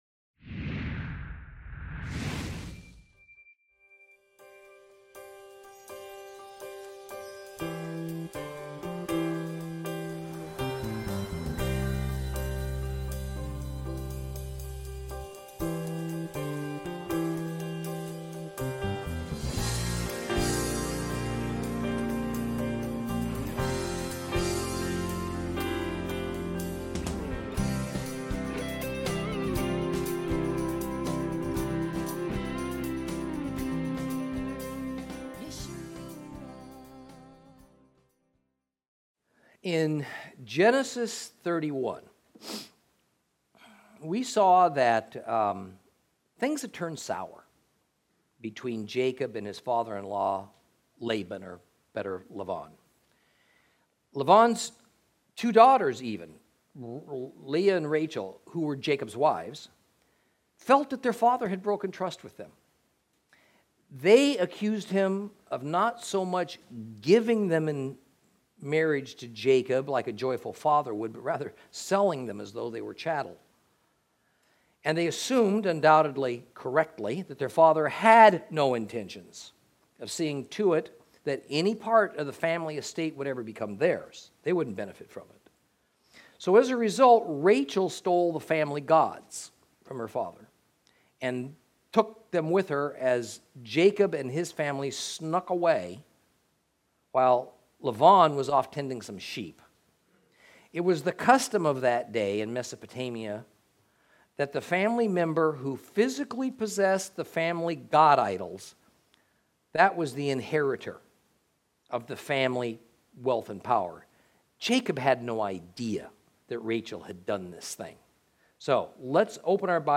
Lesson 30 Ch31 Ch32 - Torah Class